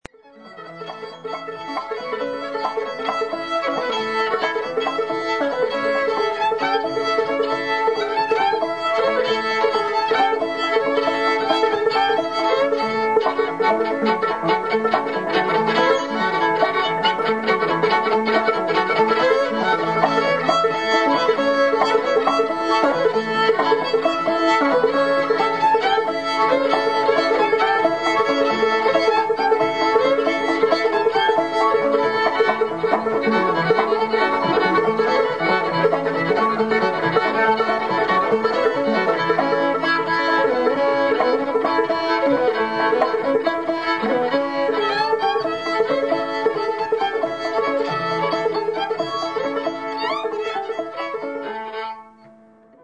Guitar
Fiddle
Banjo